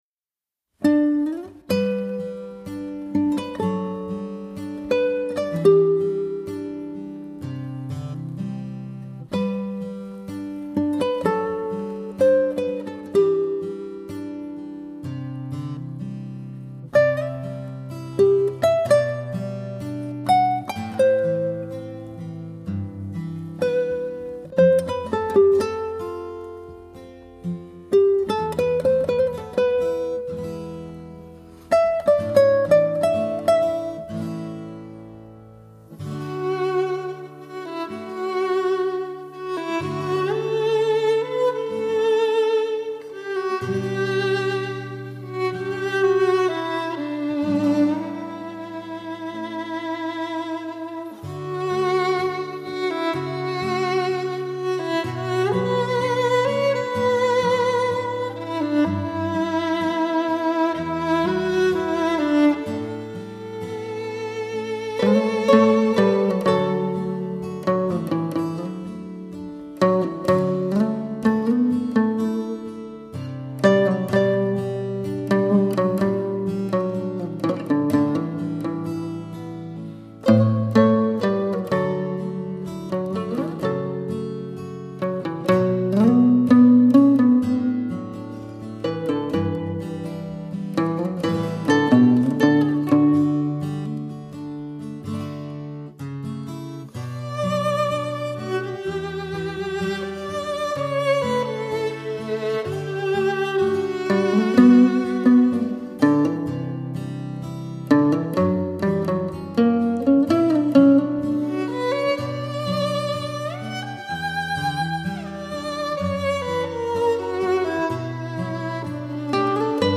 中阮 & 中西乐器的奇妙音乐际遇
阮咸/小提琴/尼龙吉他/原声吉他/电贝斯/民族打击乐器